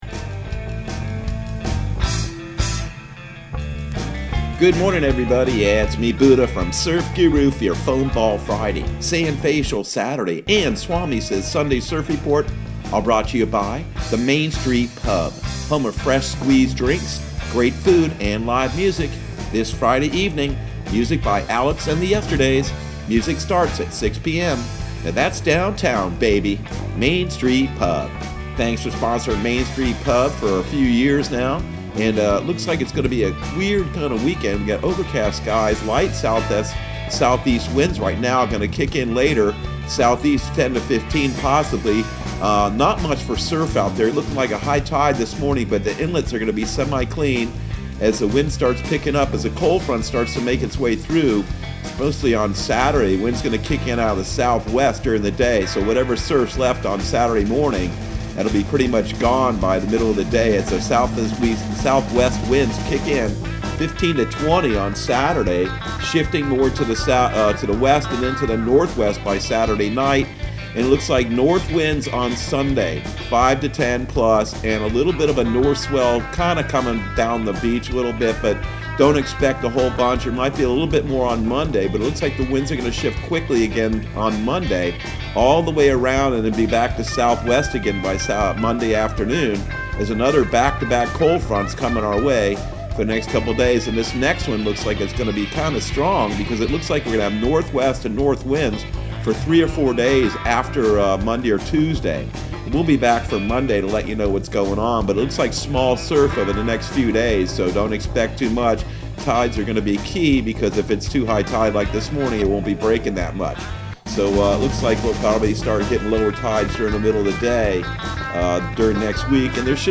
Surf Guru Surf Report and Forecast 12/04/2020 Audio surf report and surf forecast on December 04 for Central Florida and the Southeast.